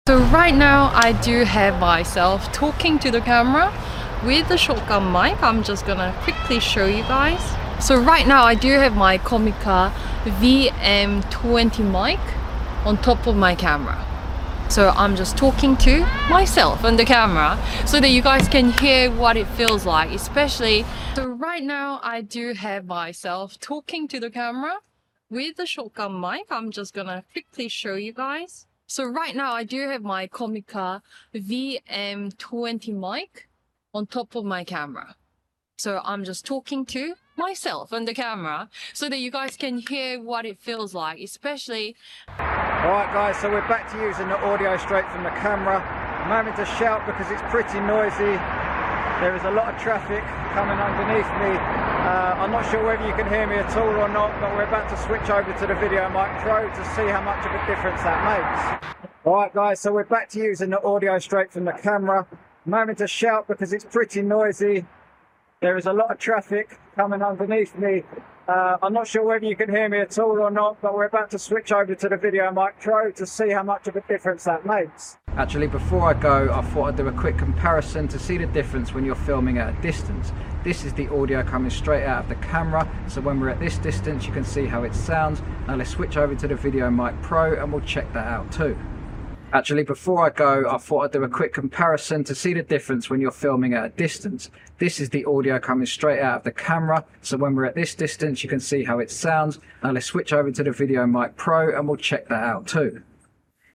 هندسة صوتية وازالة الضوضاء والتشويش وتنقية الصوت باحترافية 3